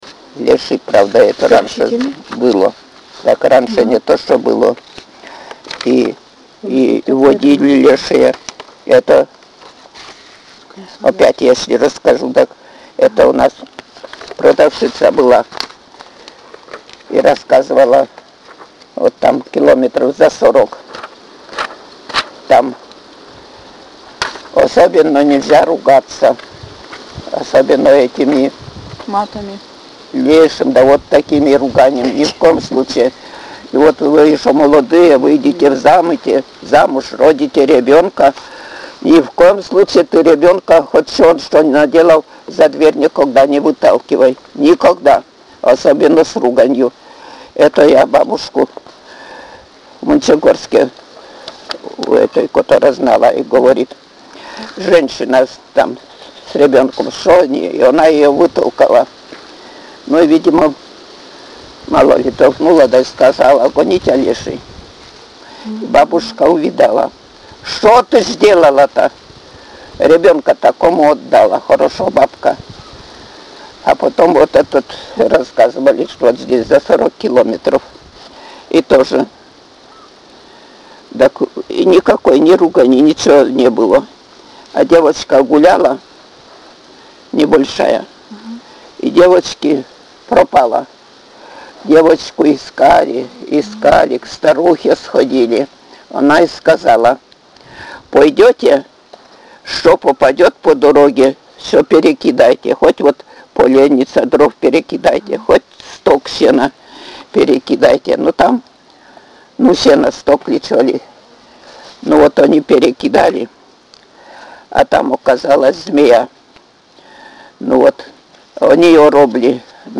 «Леший, правда, это раньше было…» — Говор северной деревни
Пол информанта: Жен.
Место записи: Плах.
Аудио- или видеозапись беседы: